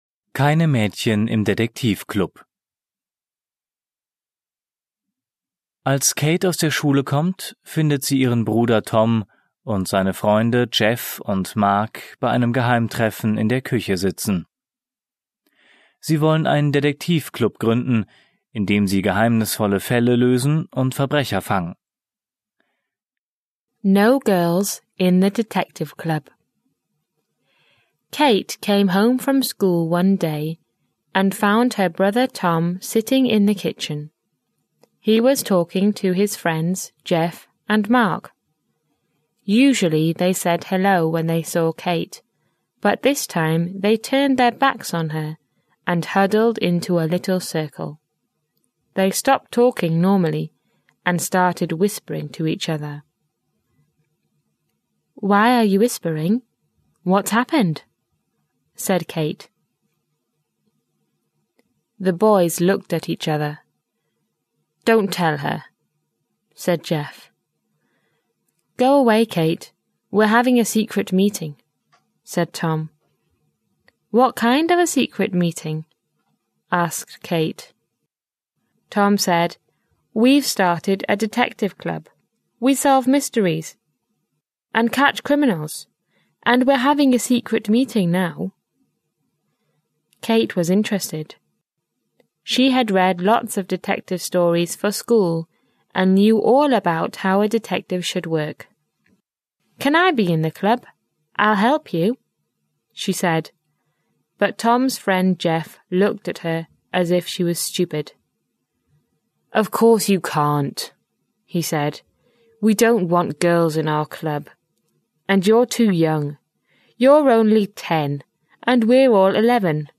Vor jeder englischen Geschichte ist eine kurze Einführung auf Deutsch gegeben, die den Inhalt der Geschichte kurz zusammenfasst. Alle Geschichten sind von englischen Muttersprachlern gesprochen, um den Kindern ein Gefühl für die Sprachmelodie und Aussprache zu vermitteln.